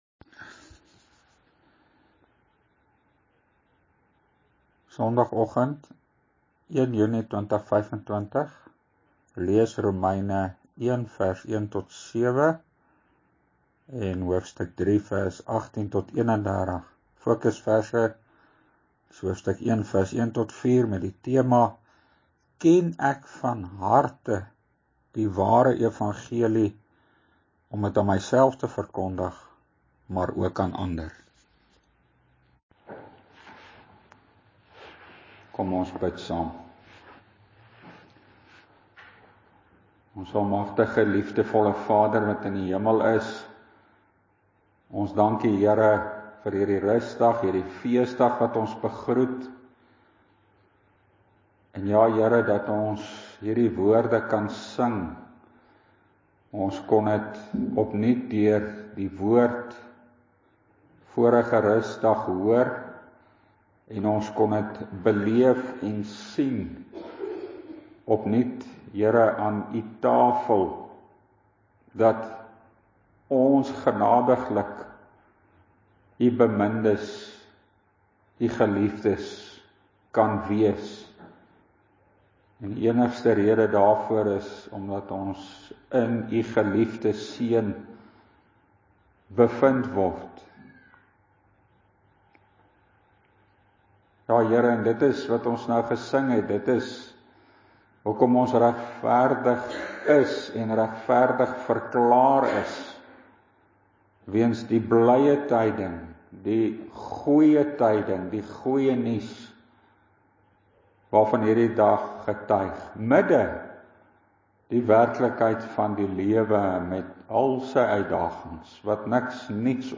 -‘n Artikel en preek om ons tot ware verootmoediging en bekering te bring, of ons nou protestant of roomsgesinde is –